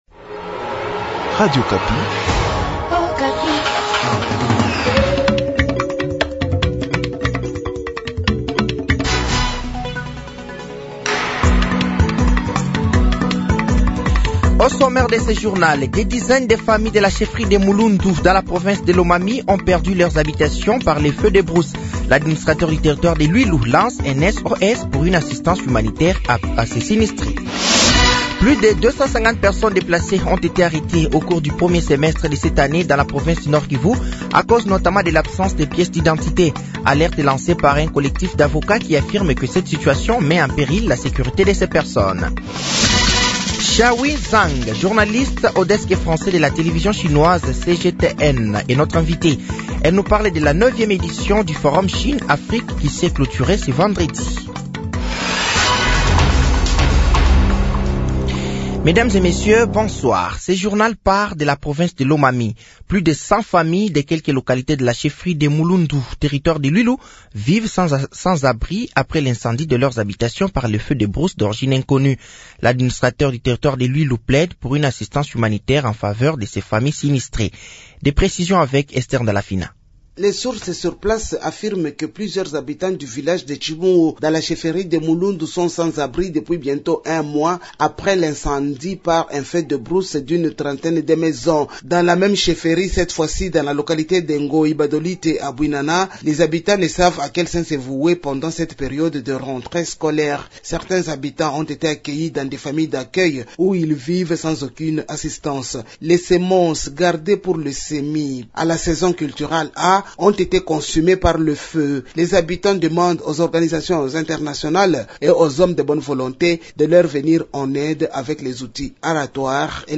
Journal français de 18h de ce samedi 07 septembre 2024